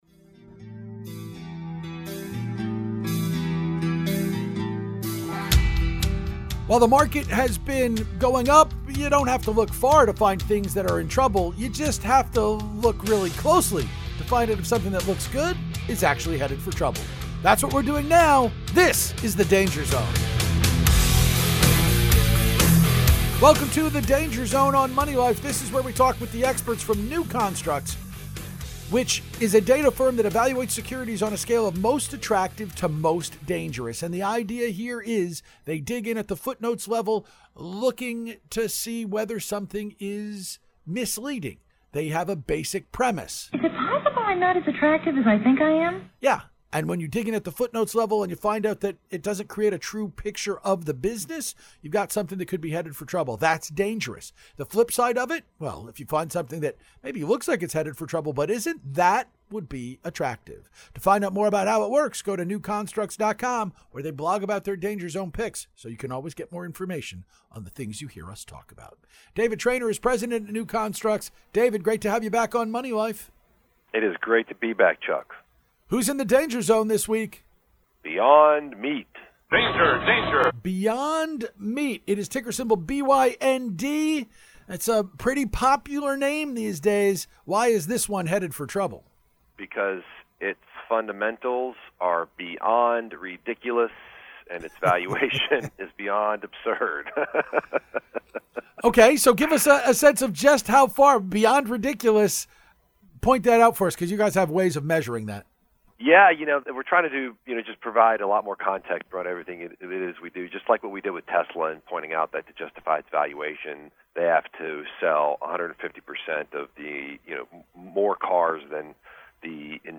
Danger Zone interview